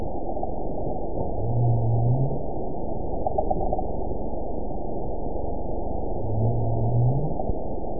event 910910 date 02/01/22 time 15:47:32 GMT (3 years, 9 months ago) score 7.78 location TSS-AB05 detected by nrw target species NRW annotations +NRW Spectrogram: Frequency (kHz) vs. Time (s) audio not available .wav